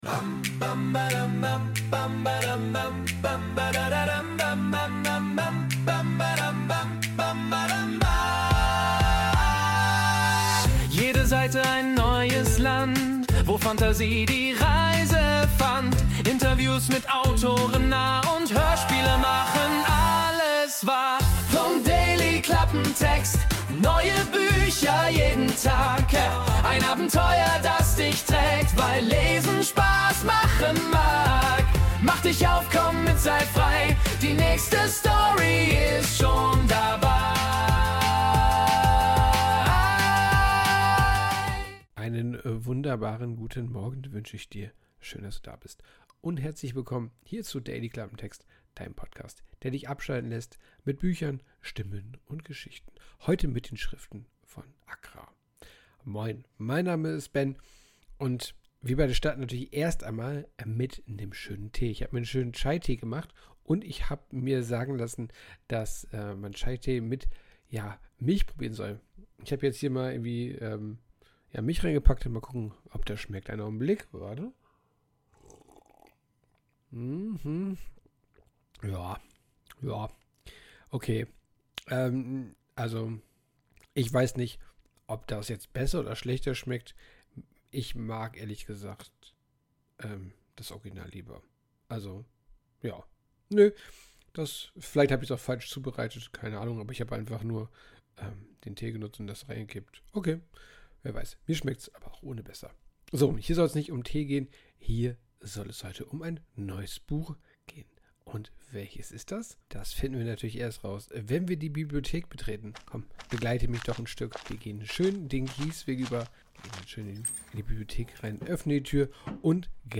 Orchestral Sweeping Dramatic Music
Intromusik: Wurde mit der KI Suno erstellt.